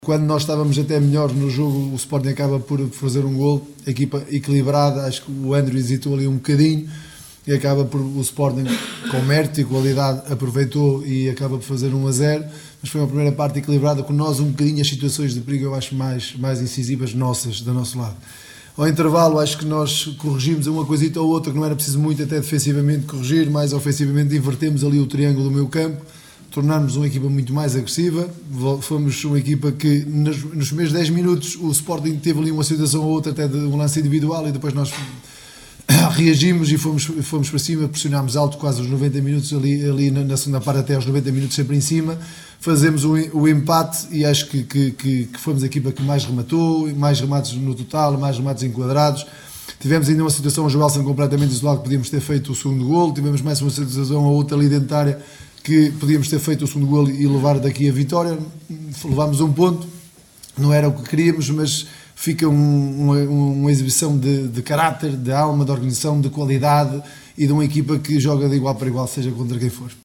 No final da partida, Cesar Peixoto, treinador gilista, valorizou a prestação da equipa.